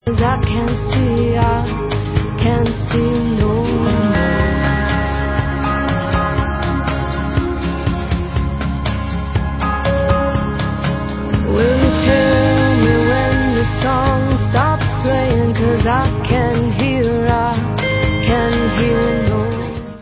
Písničkáři